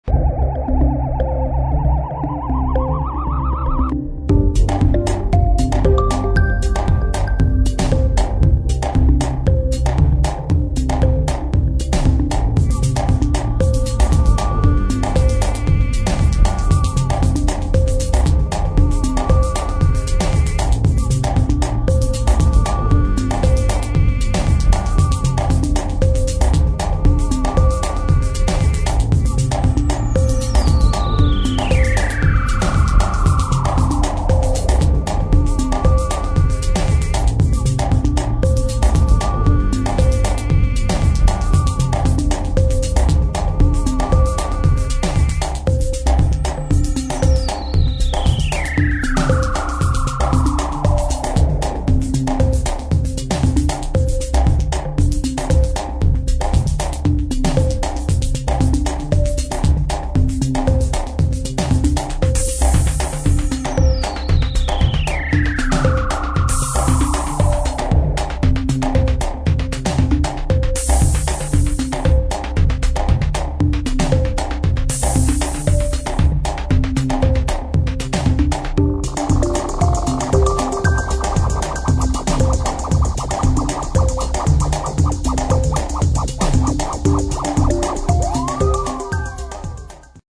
[ DEEP HOUSE / DEEP MINIMAL / COSMIC DISCO ]